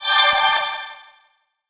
get_pickup_03.wav